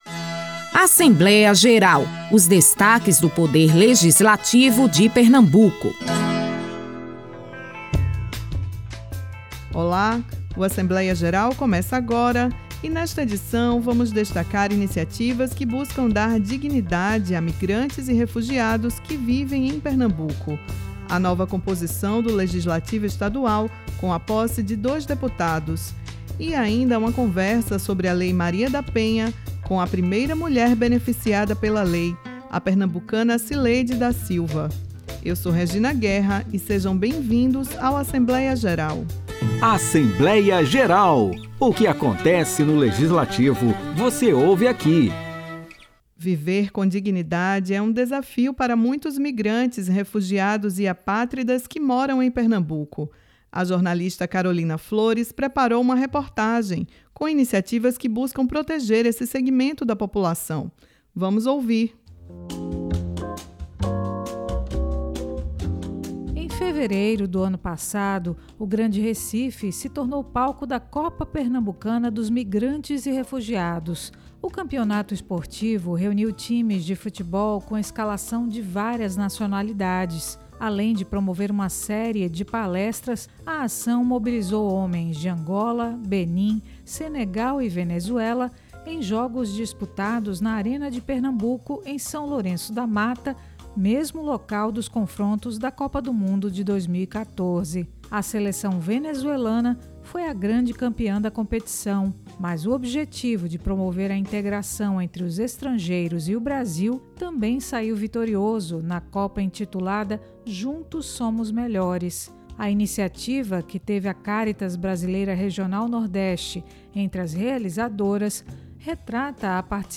Na entrevista, alguns avanços conquistados na Comissão da Defesa dos Direitos da Mulher .